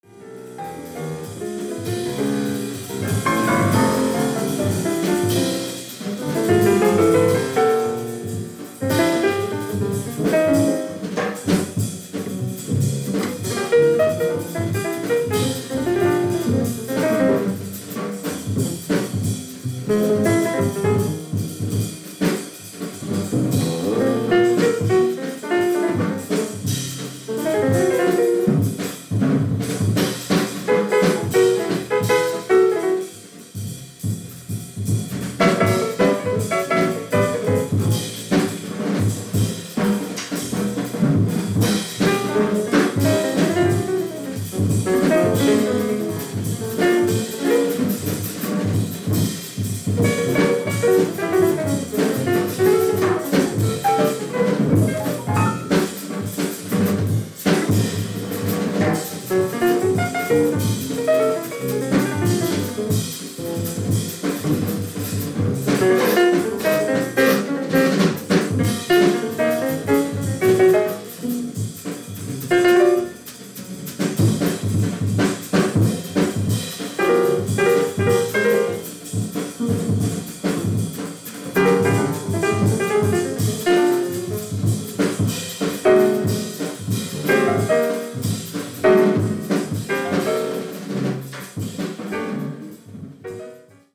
65年・コペンハーゲン録音されたアルバムです。